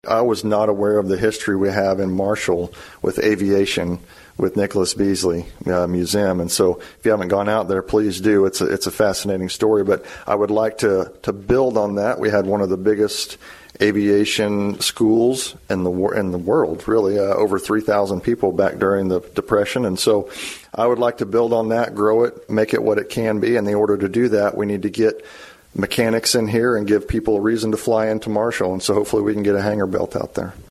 He tells KMMO News that Marshall has an opportunity to build on its role in aviation history.